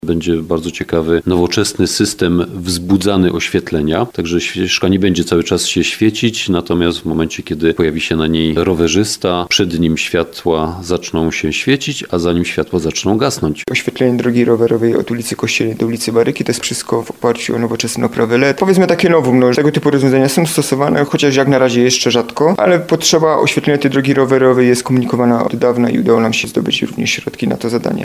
O SZCZEGÓŁACH MÓWIĄ BURMISTRZ MIASTA, KRYSTIAN GRZESICA I JEGO ZASTĘPCA, SEBASTIAN MACIOŁ - POSŁUCHAJ